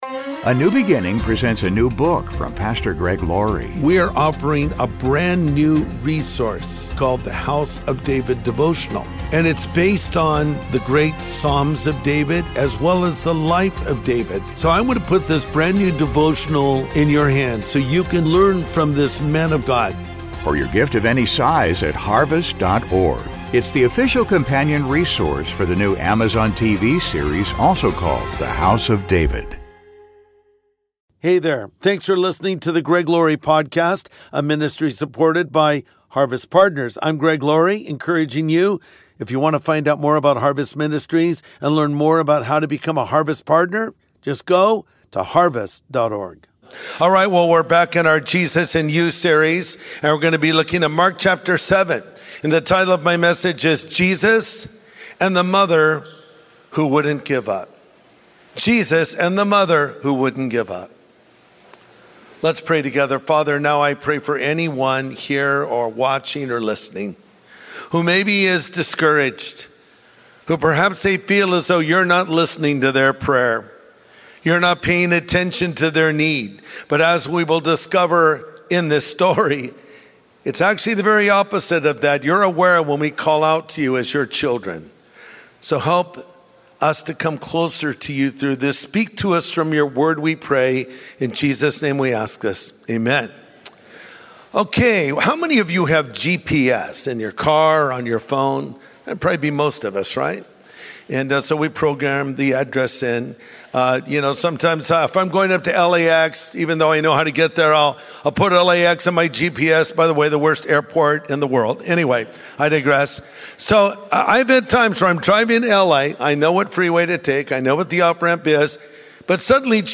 Jesus and the Mother Who Wouldn't Give Up | Sunday Message